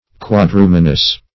Search Result for " quadrumanous" : The Collaborative International Dictionary of English v.0.48: Quadrumanous \Quad*ru"ma*nous\, a. (Zool.) Having four hands; of or pertaining to the Quadrumana.